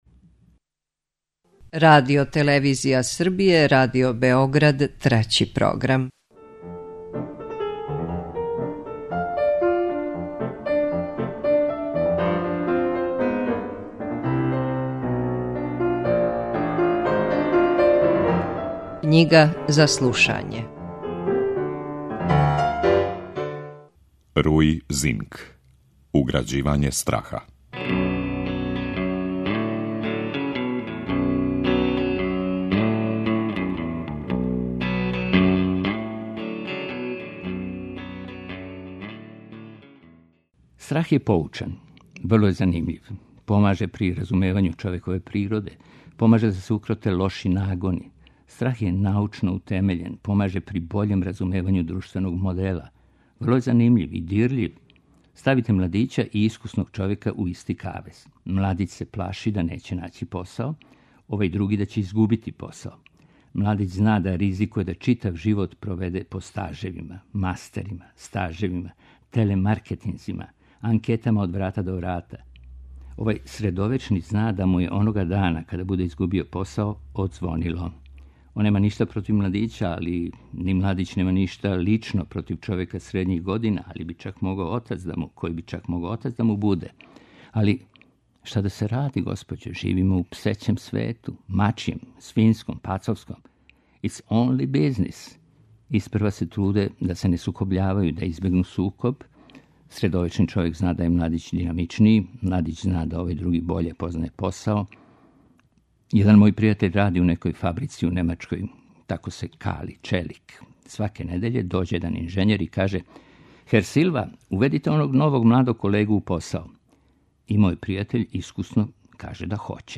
Књига за слушање
У емисији Књига за слушање, вечерас можете пратити осми наставак романа Уграђивање страха, чији је аутор португалски писац Руи Зинк.